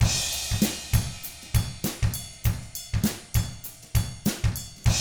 99FUNKY4T1-R.wav